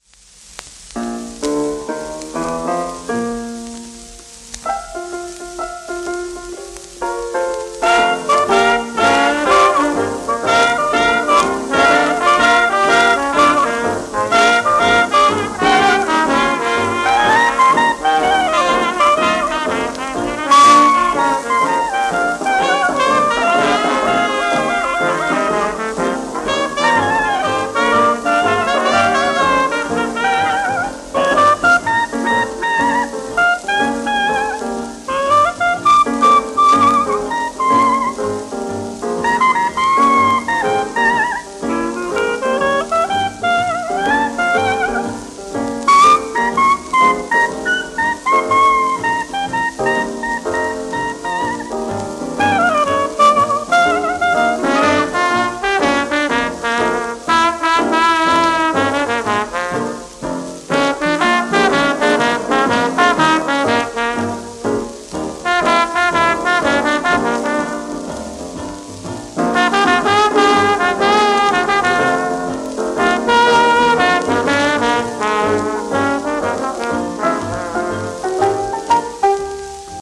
盤質A-/B+ *外周から2cm程度の薄いクラック(ヒビ)あり、小キズ
外周クラック部分を含みレコードプレーヤーで再生しておりますので、音への影響をご確認ください